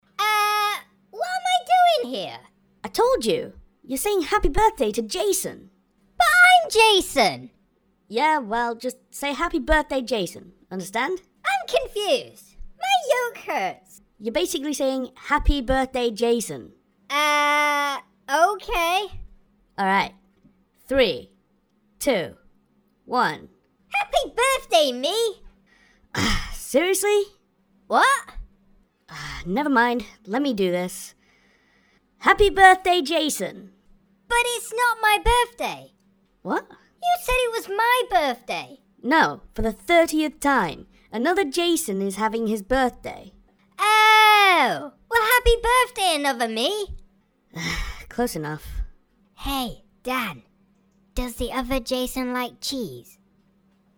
Decided to give another surprise to the dude, a voice skit!